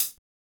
Closed Hats
boom bap 1 (hat).wav